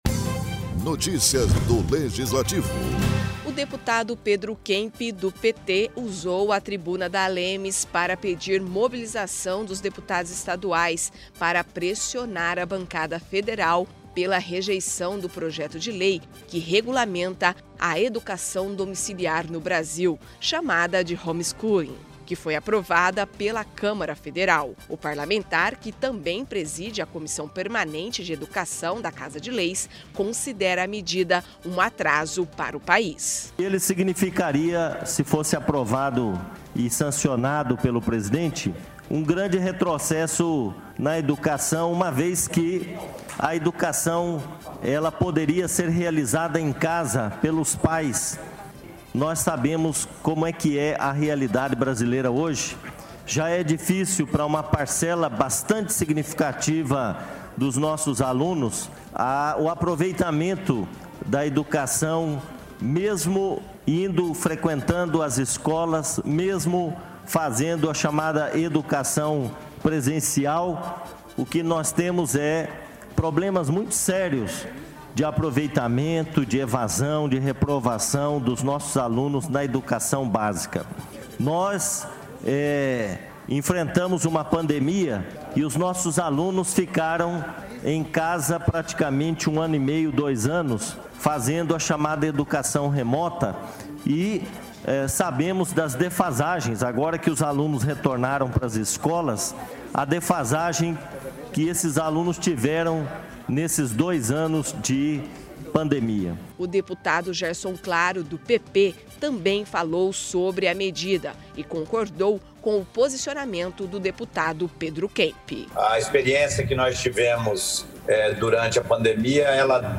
O deputado Pedro Kemp (PT), usou a tribuna da ALEMS, para pedir mobilização dos deputados estaduais para pressionar a bancada federal pela rejeição do projeto de lei que regulamenta a educação domiciliar no Brasil, chamada homeschooling, que foi aprovado pela Câmara Federal.